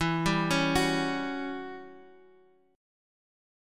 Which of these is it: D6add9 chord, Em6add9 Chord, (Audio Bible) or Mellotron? Em6add9 Chord